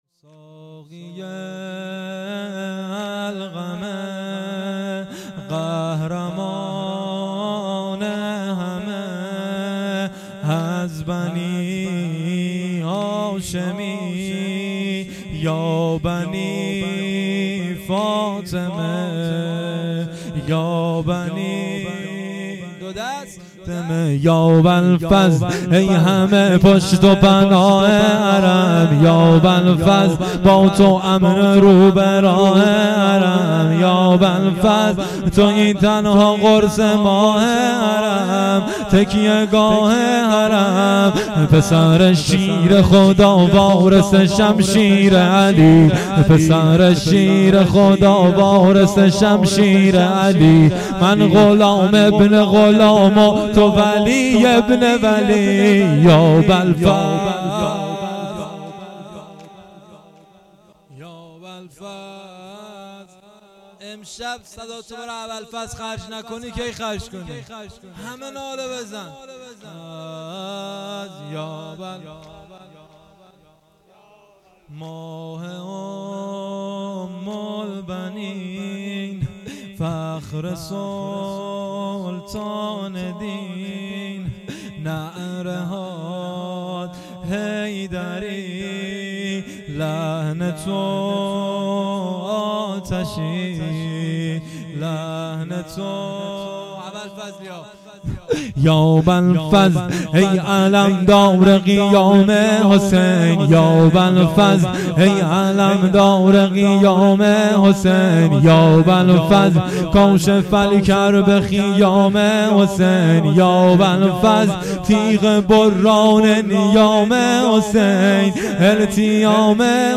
مراسم عزاداری دهه اول محرم الحرام 1399 - مسجد صاحب الزمان (عج) هرمزآباد
شب نهم - محرم 99